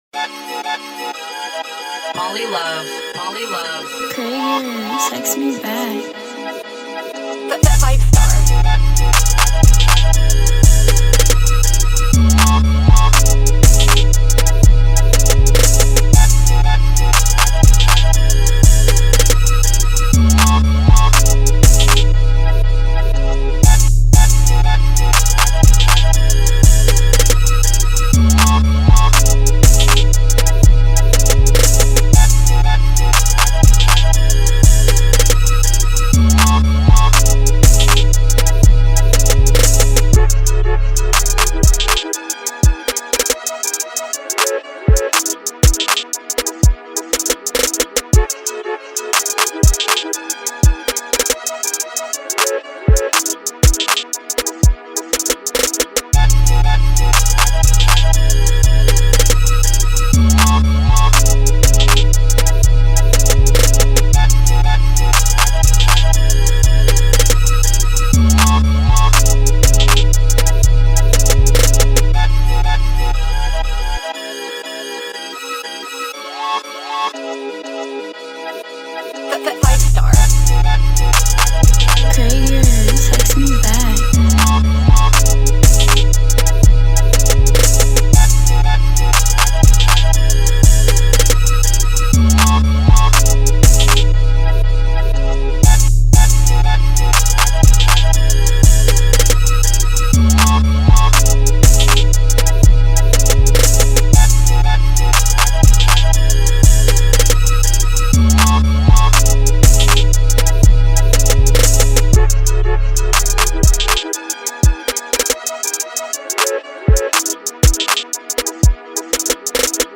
Here's the official instrumental